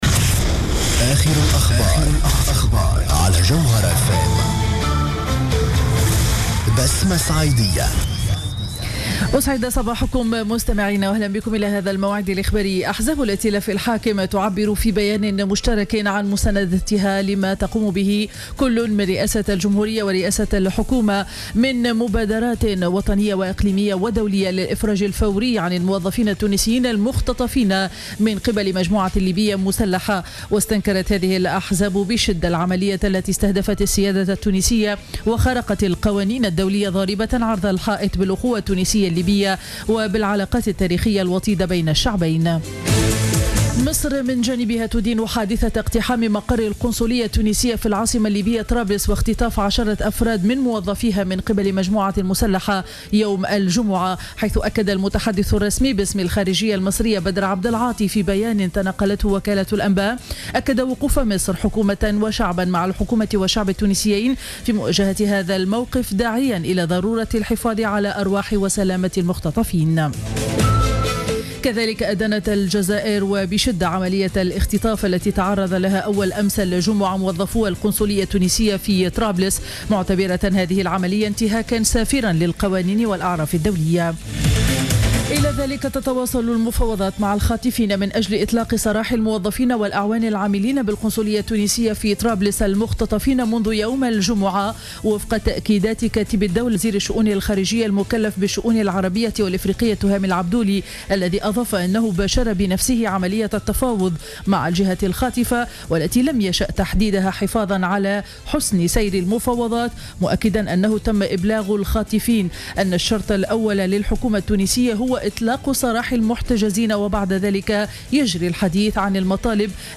نشرة أخبار السابعة صباحا ليوم الأحد 14 جوان 2015